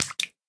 Minecraft Version Minecraft Version 25w18a Latest Release | Latest Snapshot 25w18a / assets / minecraft / sounds / mob / guardian / flop1.ogg Compare With Compare With Latest Release | Latest Snapshot
flop1.ogg